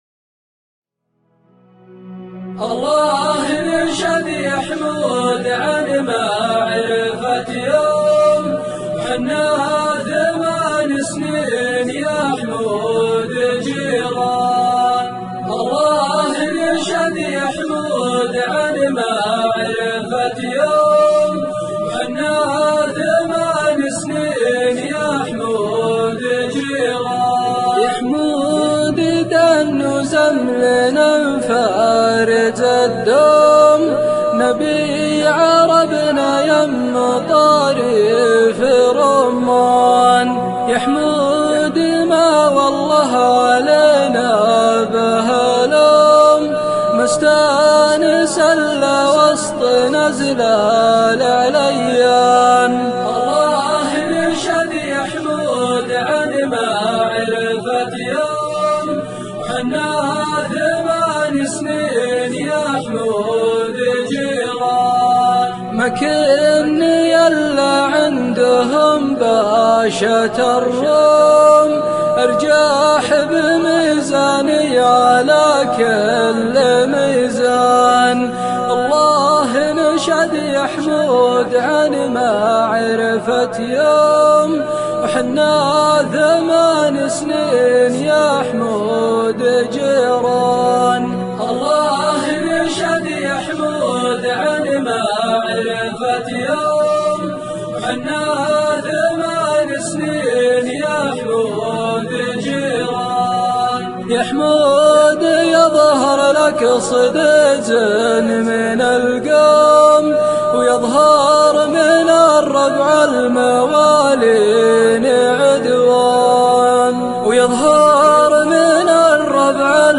وتم العمل على إنشادها بصوت مليء بالإحساس الدال